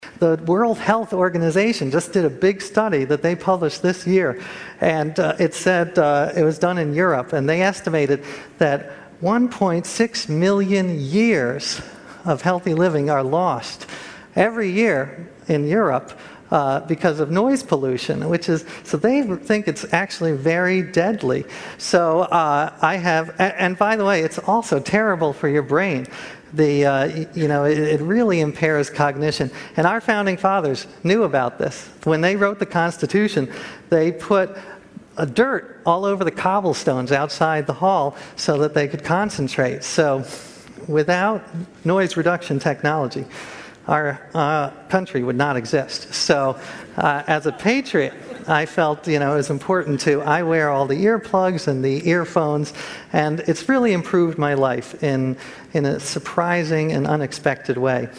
TED演讲：为什么健康的生活方式几乎把我害死(5) 听力文件下载—在线英语听力室